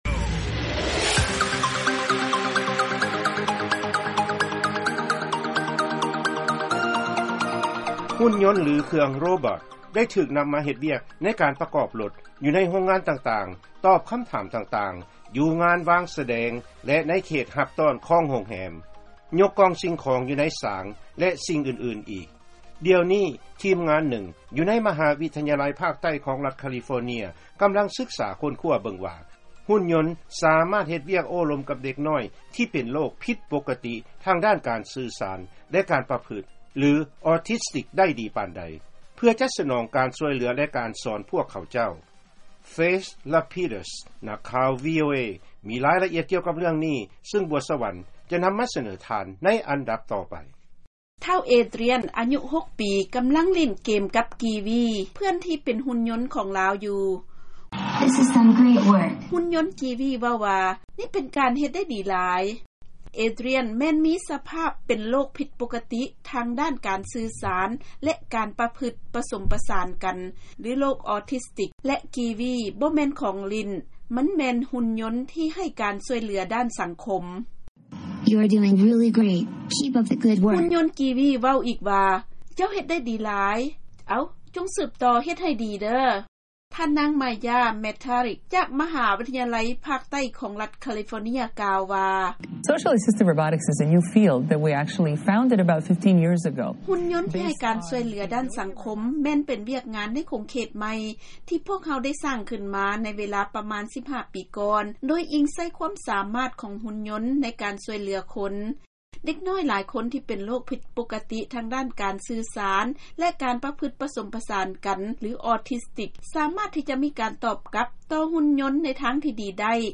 ເຊີນຟັງລາຍງານເລື້ອງຫຸ່ນຍົນຊ່ວຍເດັກນ້ອຍທີ່ເປັນໂຣກ ອໍທິສຕິກ ຮຽນຮູ້ໄດ້